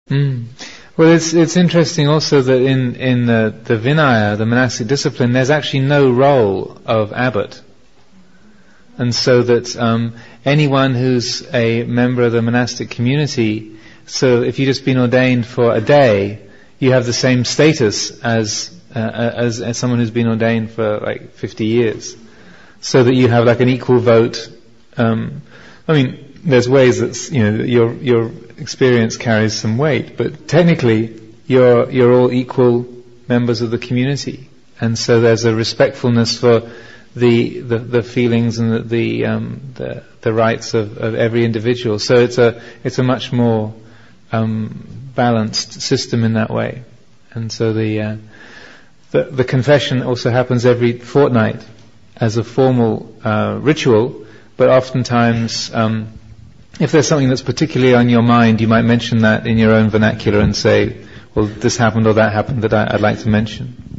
[0:05] In the Vinaya (monastic discipline) there’s no role of abbot. Reflection